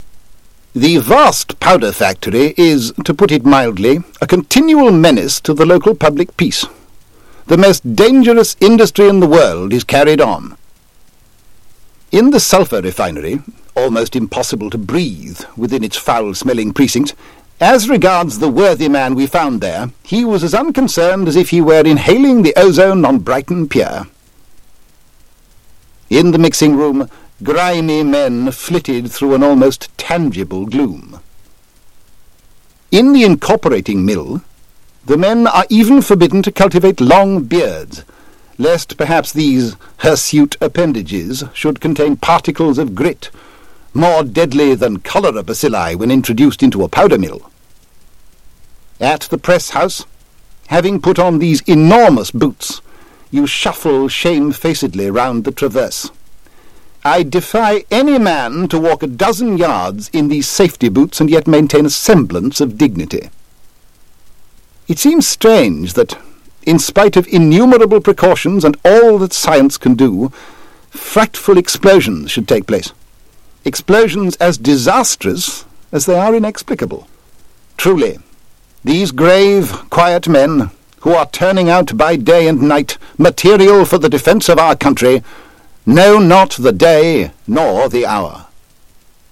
WAOH - Waltham Abbey Oral History
waoh-04-02-telephone talk.mp3